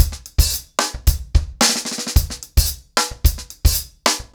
TimeToRun-110BPM.31.wav